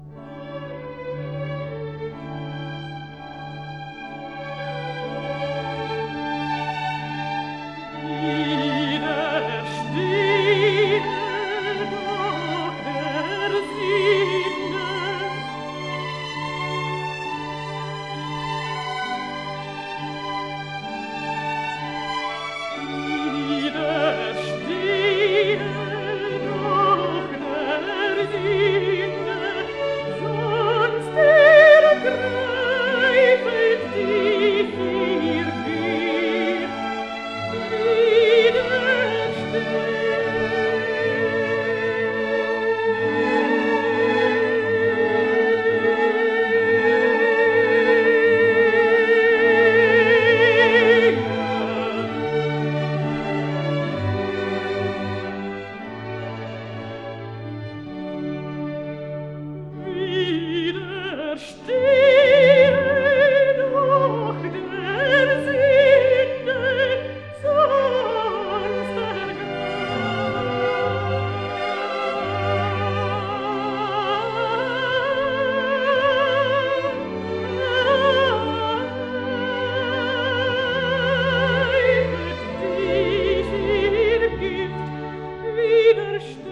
contralto
(harpsichord and director)
1958 stereo recording